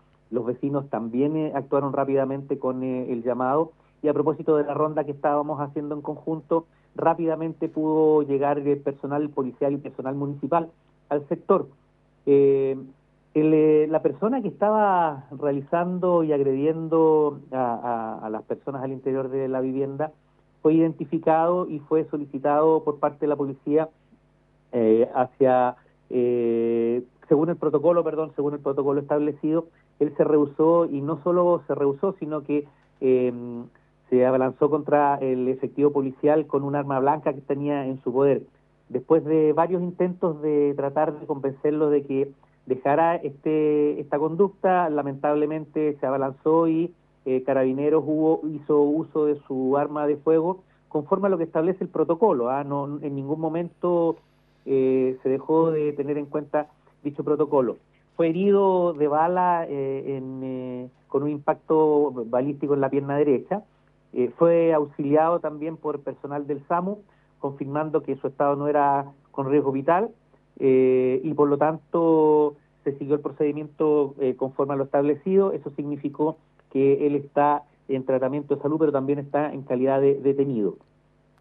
En el programa “La Mañana en Directo” de Radio Observador de este lunes 27 de enero, entregó más detalles del suceso el alcalde subrogante de La Cruz, Oscar Calderón.
ENTREVISTA-OSCAR-CALDERON-27-ENERO-mp3cut.net_.mp3